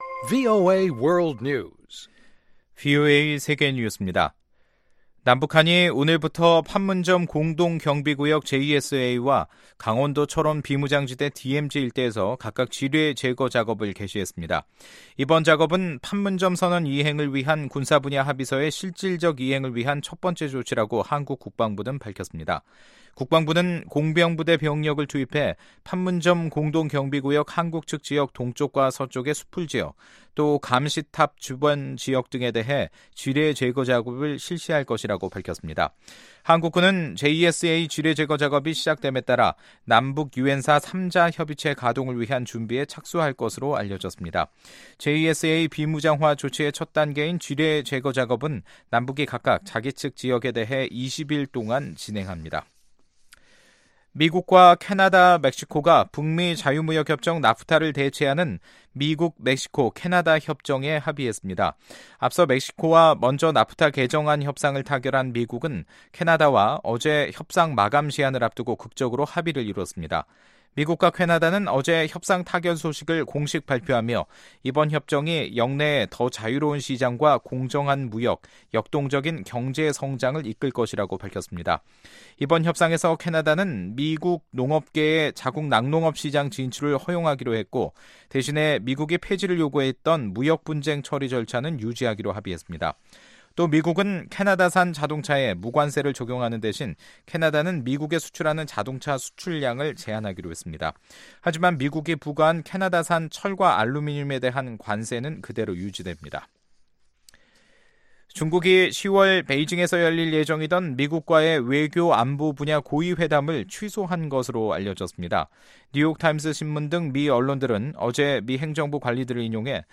VOA 한국어 간판 뉴스 프로그램 '뉴스 투데이', 2018년 10월 1일 2부 방송입니다. 트럼프 미국 대통령은 국내 중간선건 지원 유세에서 자신과 김정은 북한 국무위원장의 관계를 서로 사랑에 빠졌다고 표현했습니다. 제73차 유엔총회에서 많은 나라 대표들은 한반도에 조성된 대화 분위기를 환영하고, 제재 완화를 촉구하는 등 과거와 크게 달라진 입장을 나타냈습니다.